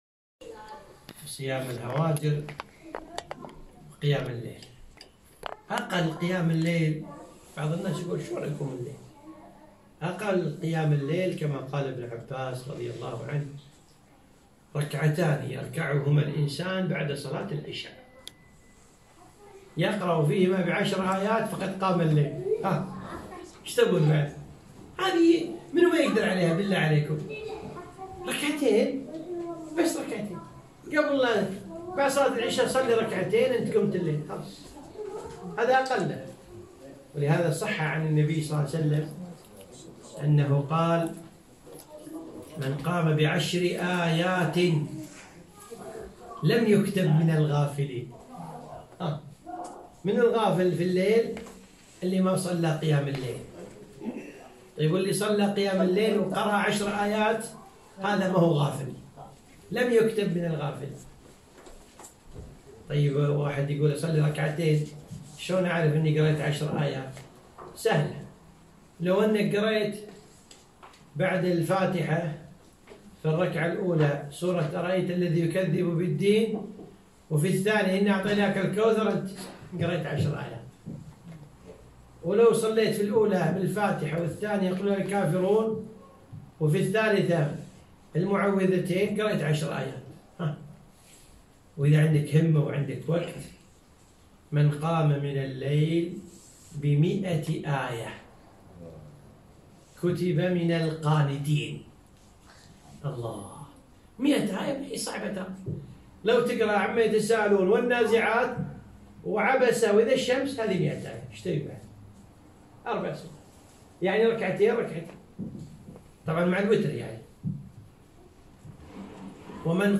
محاضرة - التعاون على البر والتقوى